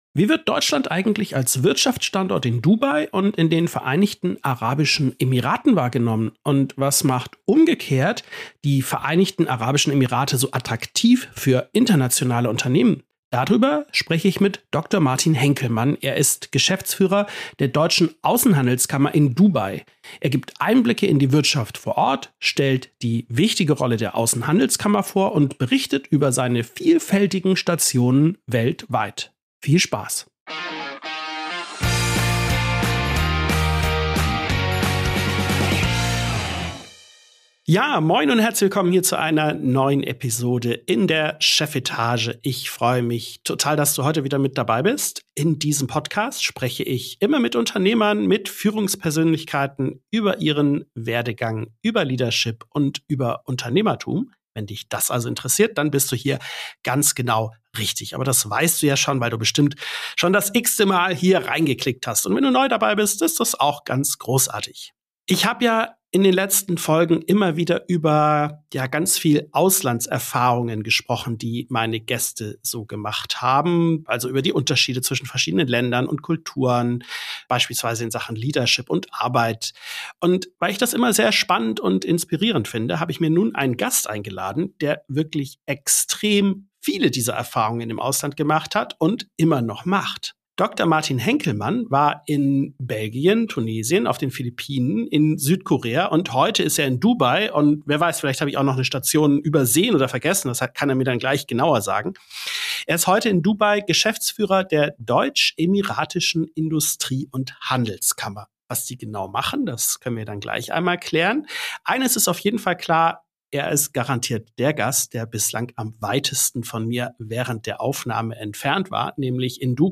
CEOs, Unternehmer und Führungskräfte im Gespräch Podcast